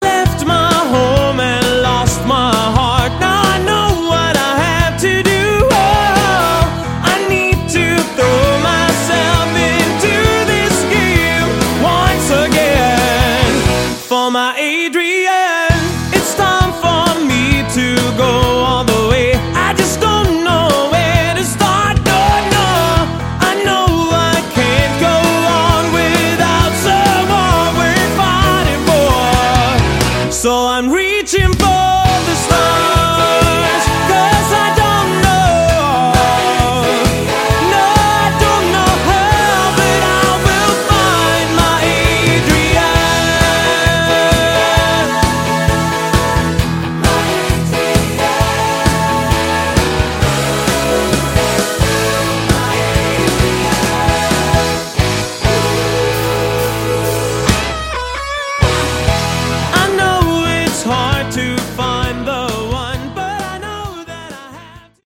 Category: AOR
vocals
guitar
keyboard
bass
drums